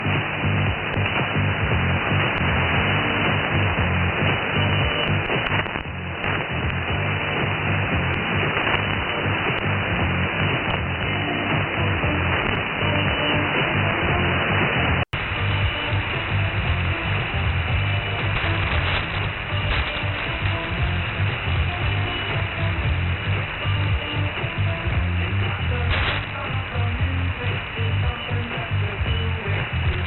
For the comparison I made audio recordings of the two receivers.
Second 0-15 >> SDRplay RSPduo
Second 15-30 >> Winradio G33DDC Excalibur Pro